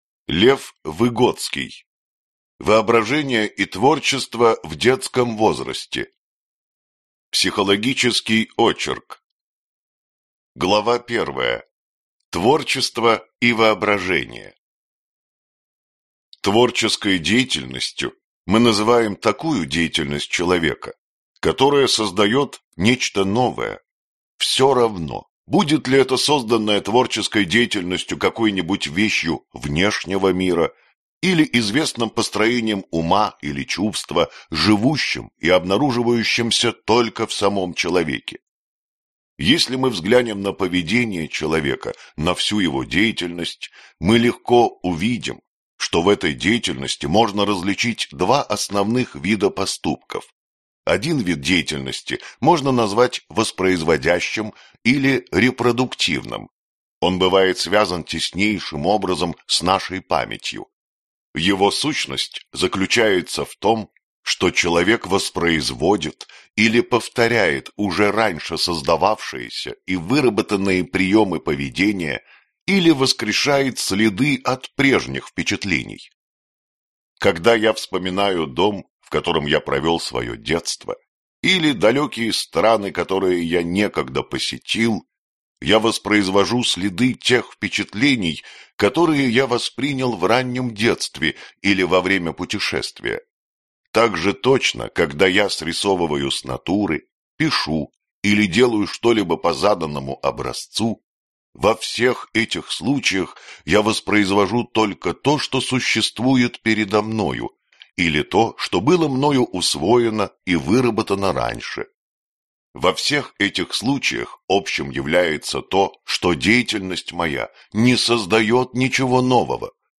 Аудиокнига Воображение и творчество в детском возрасте | Библиотека аудиокниг